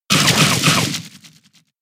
Bite_2 - Instant Sound Effect Button | Myinstants
Bite_2
bite-2_qdWg7LP.mp3